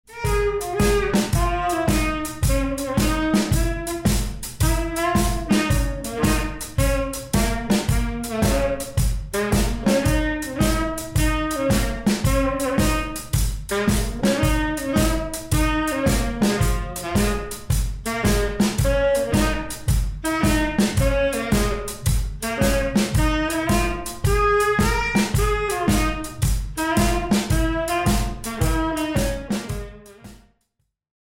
Unfortunately, too many of today’s musicians bloat their playing with endless, unimaginative, repetitive blues licks.
unending-blues-lick.mp3